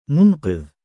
発音記号あり：مُنْقِذ [ munqidh ] [ ムンキズ ]
male_munqidh.mp3